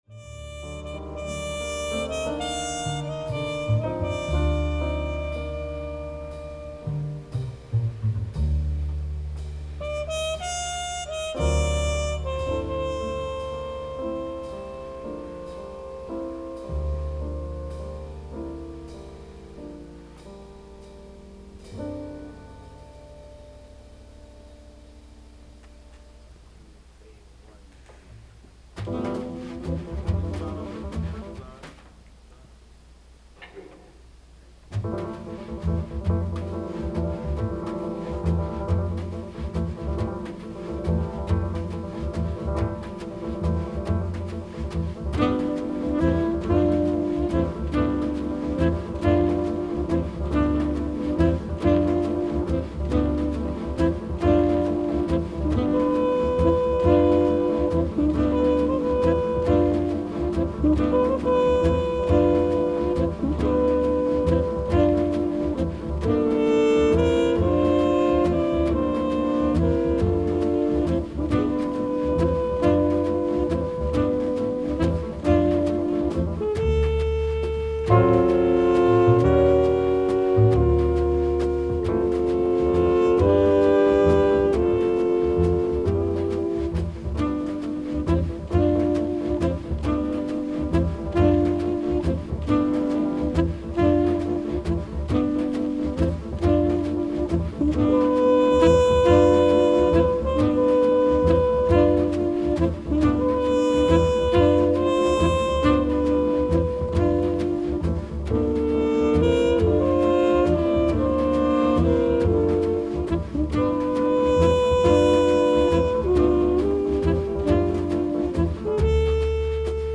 RECORDED 03/02/1959 NYC